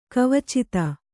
♪ kavicita